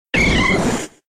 21 kio == Description == Cri de Machoc K.O. dans {{Jeu|XY}}. Catégorie:Cri de Machoc Catégorie:Cri Pokémon K.O. (X et Y) 1